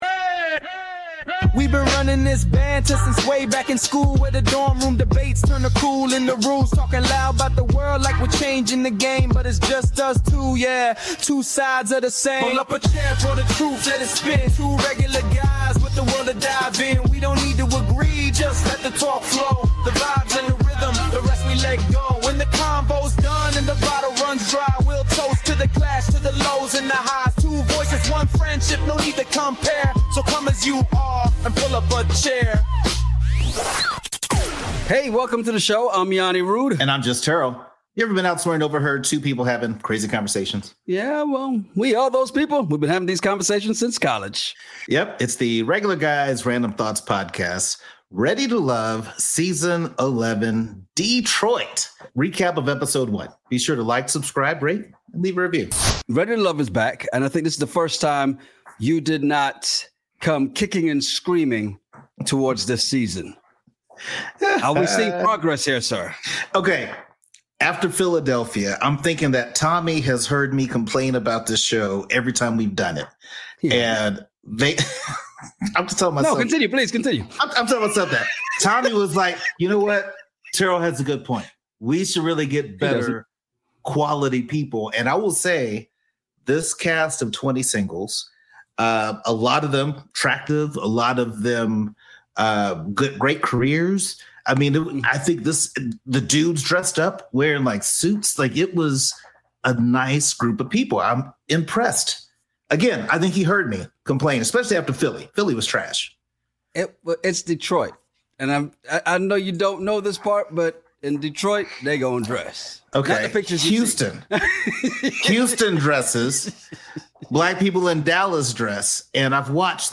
Well we are those guys and we have been having these conversations since college.